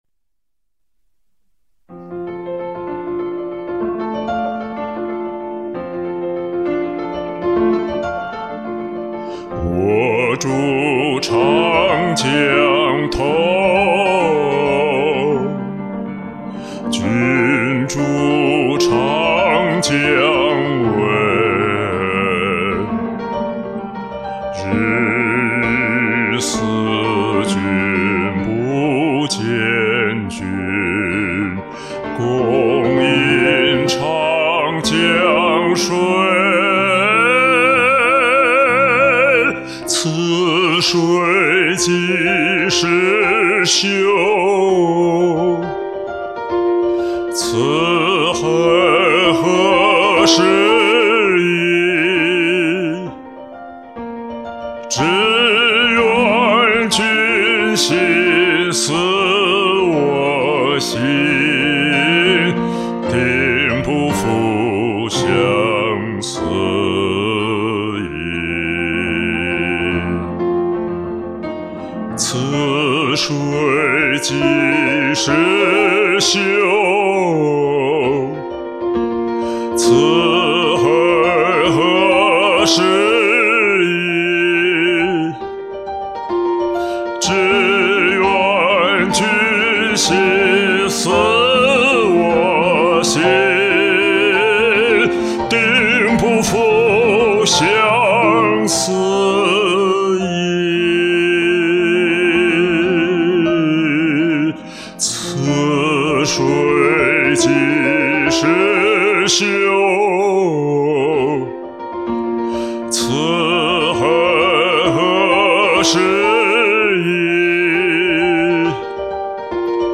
音色醇厚美丽。气息沉稳。演绎得大气深沉。太棒啦!
中低音漂亮，行家！
低音炮强大的共鸣如滔滔江水奔驰而来，极具冲击力！